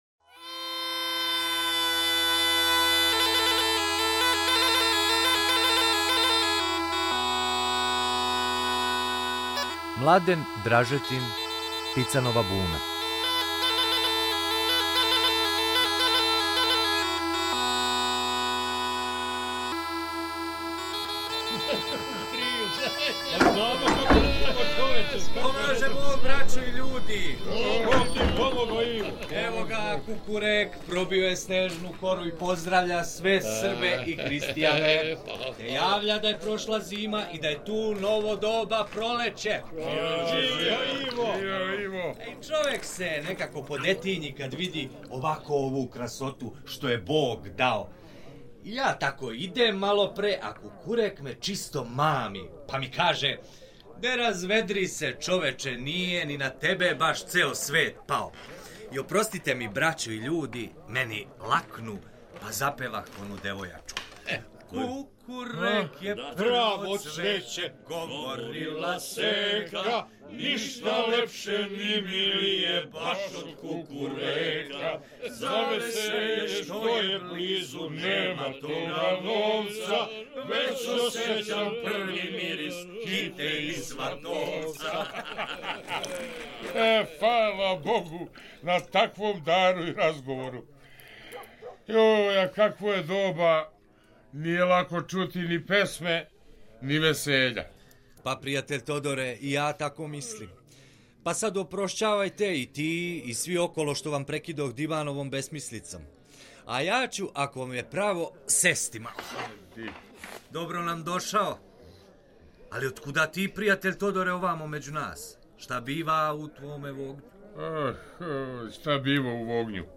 Radio drama u produkciji Radio Novog Sada.